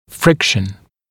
[‘frɪkʃ(ə)n][‘фрикш(э)н]трение, сила трения